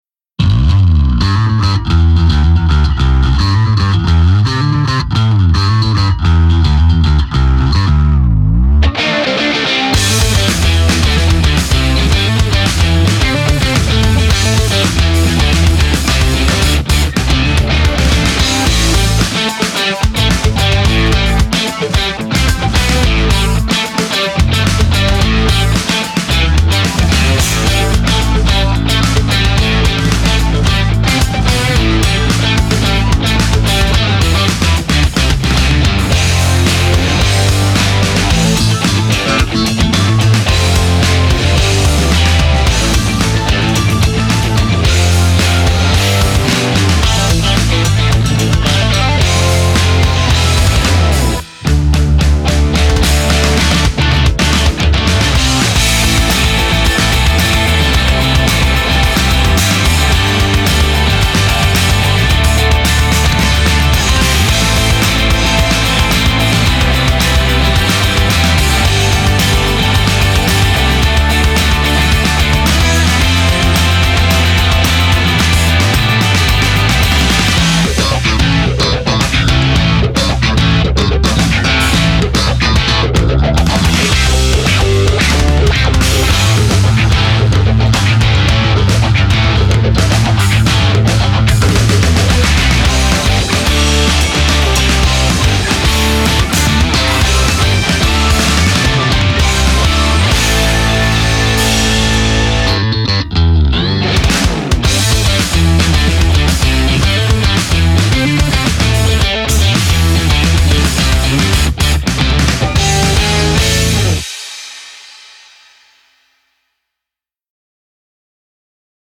BPM110
Audio QualityCut From Video